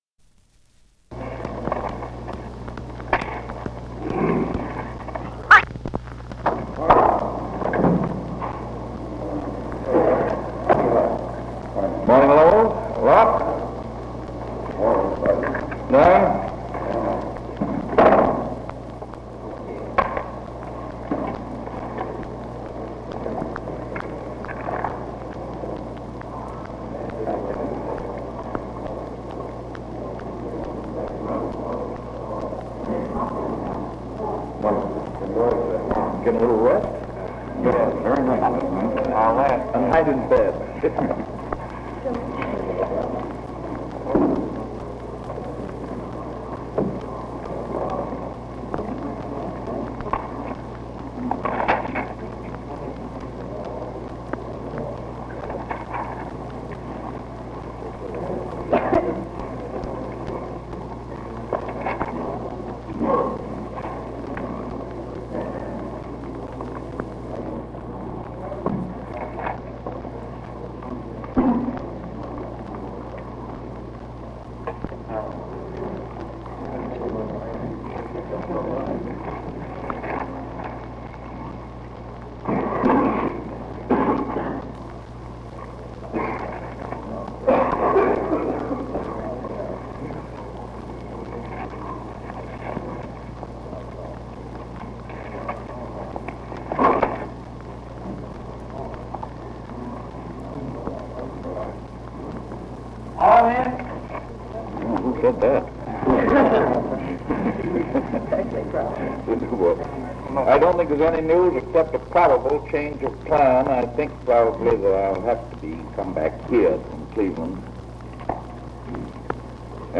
Press Conference 693